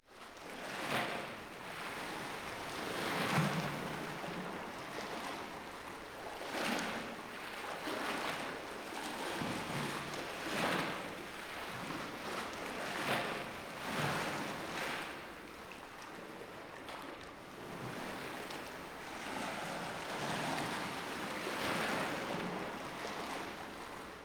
waves-fast.ogg